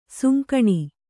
♪ sunkaṇi